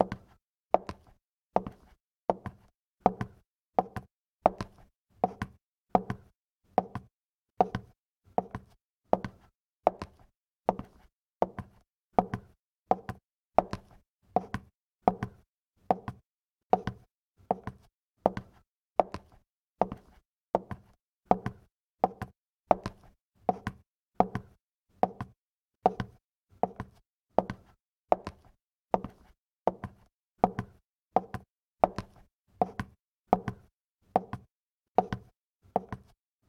石の上を歩く足音
人間 生活音
カツ、カツ……。静かな空間に響く、石の上を歩く足音。